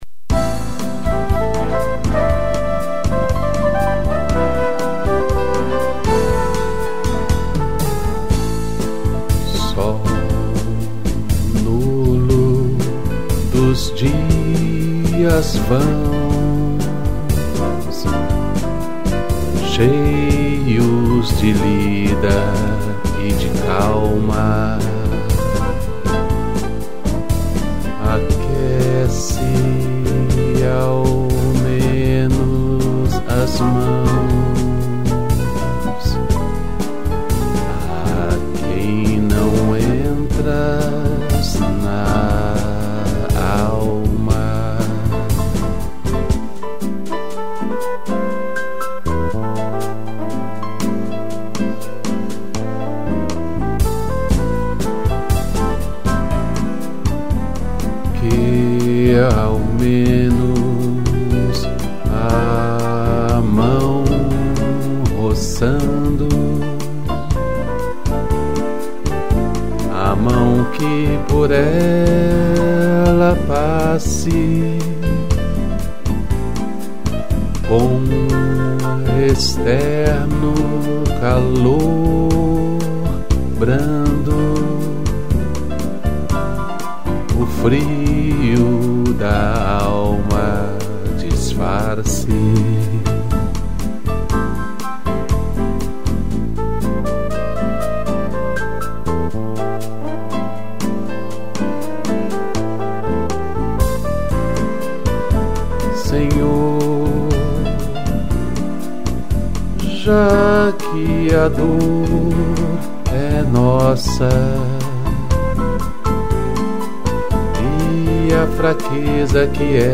piano e flugel_horn